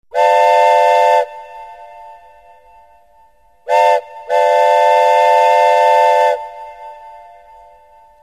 Звуки поезда
Звуковое оповещение о прибытии и отправлении поездов на вокзале (РЖД)